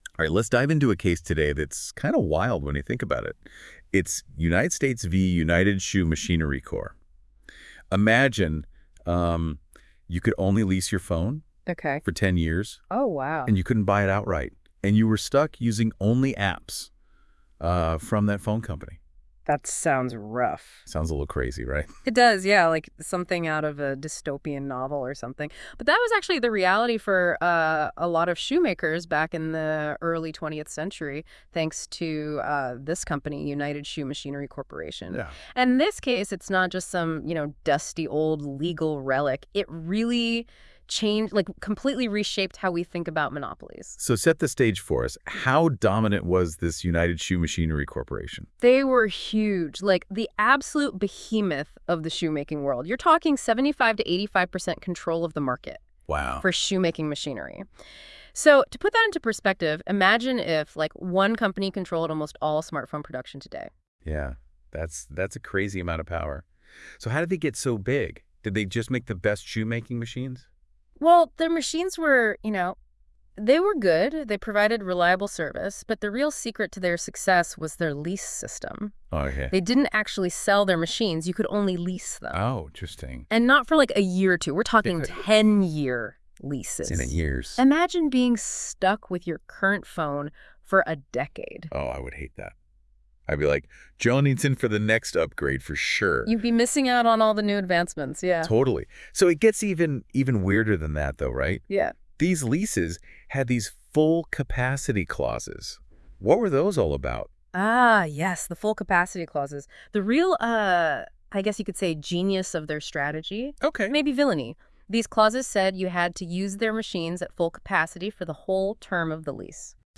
Listen to an audio breakdown of United States v. United Shoe MacHinery Corp..